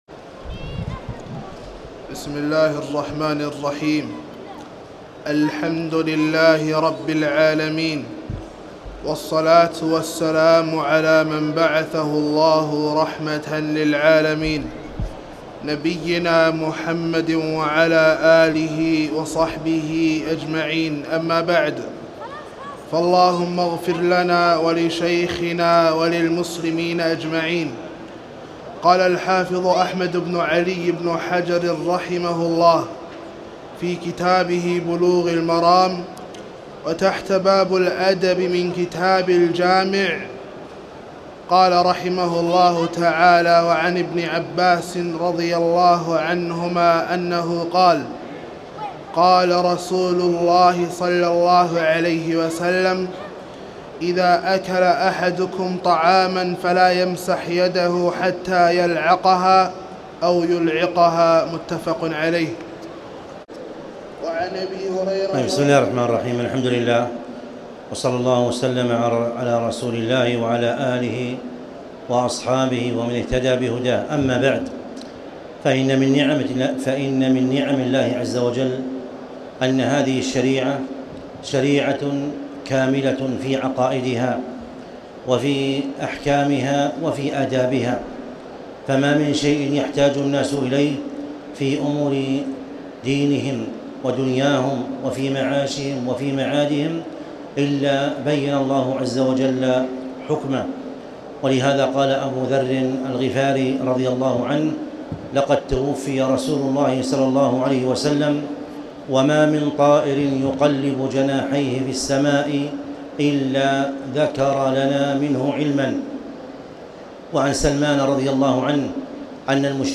تاريخ النشر ٢١ رمضان ١٤٣٨ هـ المكان: المسجد الحرام الشيخ